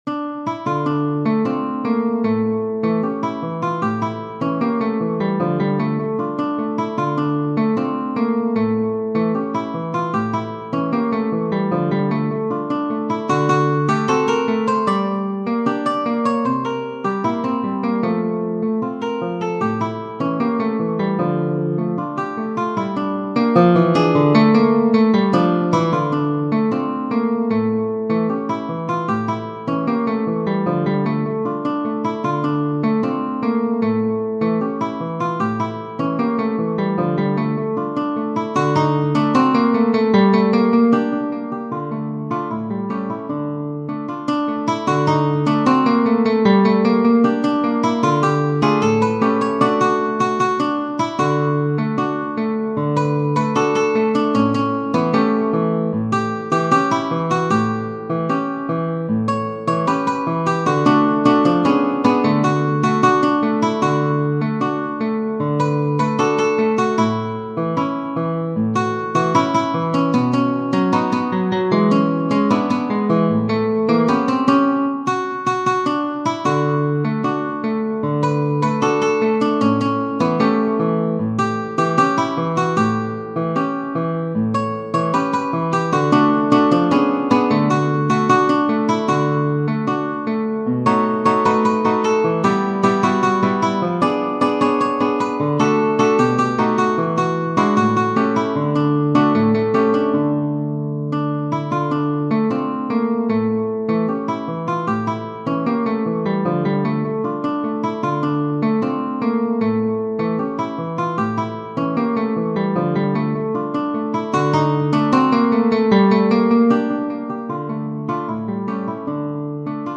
Nazareth, E. Genere: Ballabili La forma habanera giunse in Brasile nel XIX secolo.